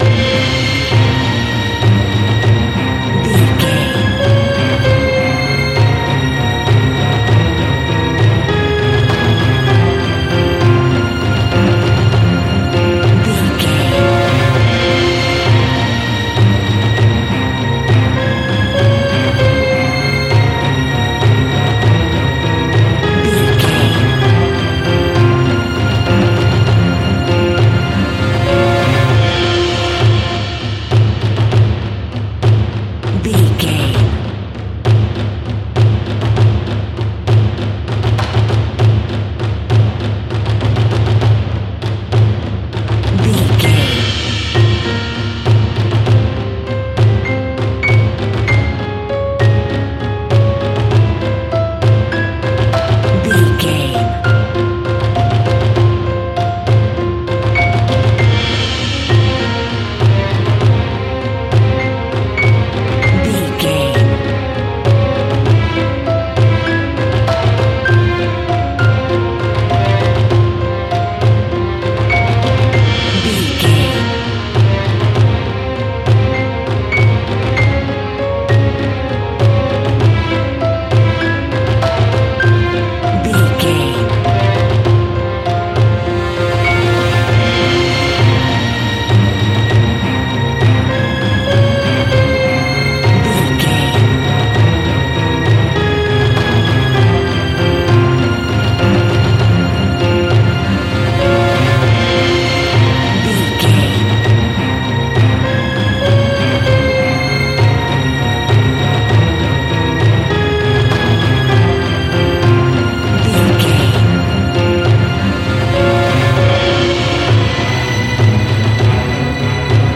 Scary Film Titles Music.
Aeolian/Minor
scary
tension
ominous
dark
suspense
dramatic
haunting
epic
driving
heavy
violin
cello
double bass
brass
drums
percussion
piano
creepy
spooky
viola
orchestral instruments